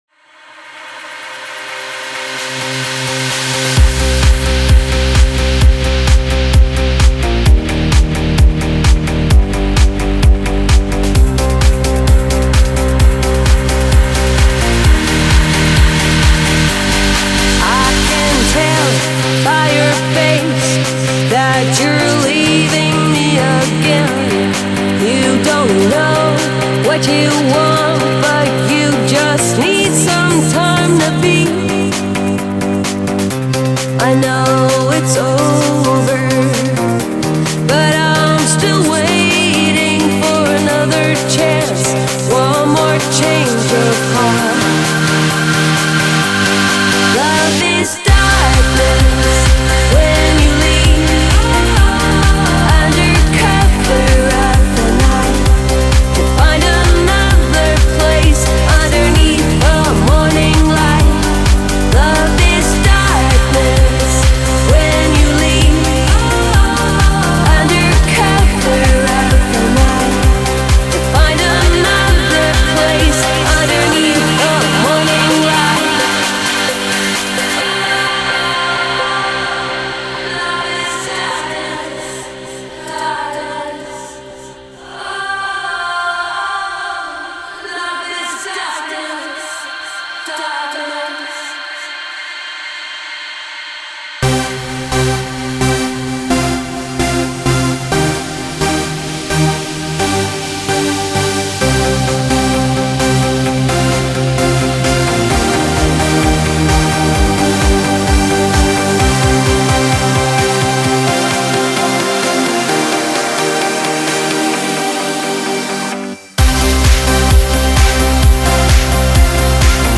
Жанр: Trance | Progressive